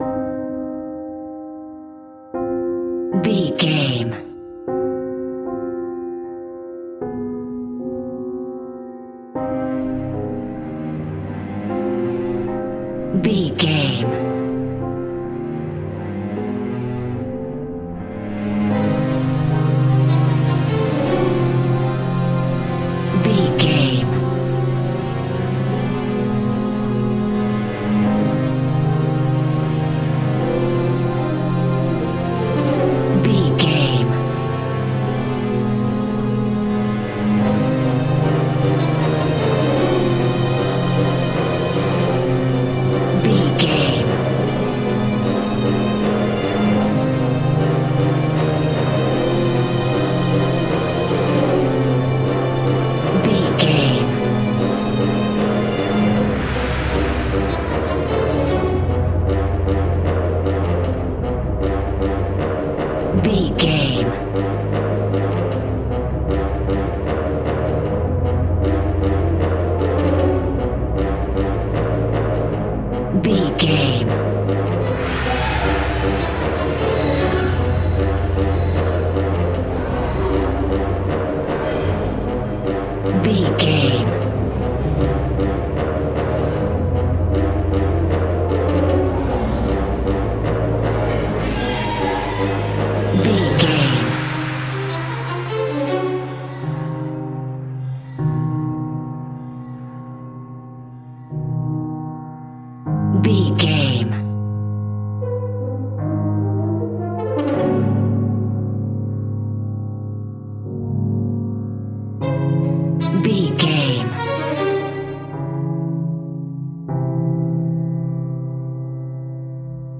In-crescendo
Aeolian/Minor
scary
tension
ominous
dark
suspense
haunting
eerie
piano
strings
brass
synth
ambience
pads